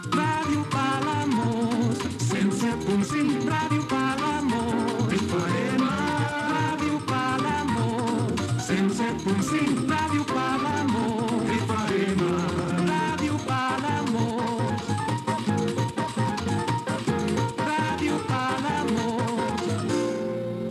Indicatiu cantat de l'emissora